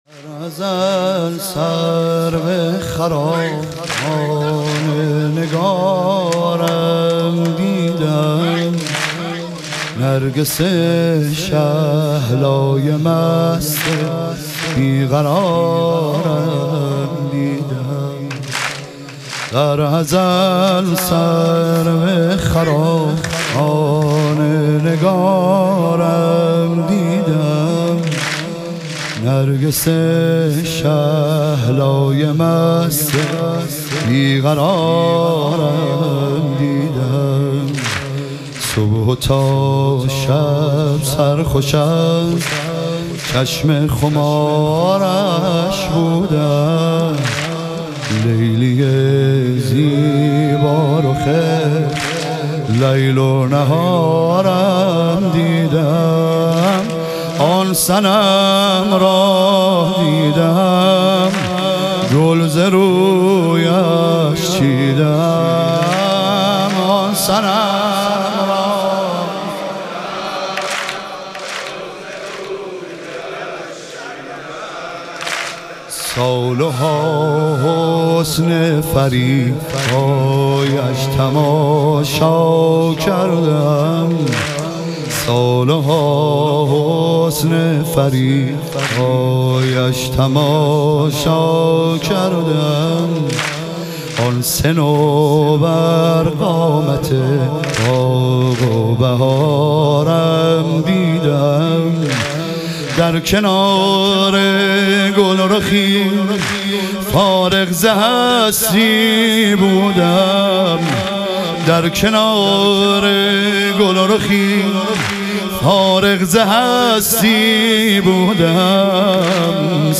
لیالی قدر و شهادت امیرالمومنین علیه السلام - واحد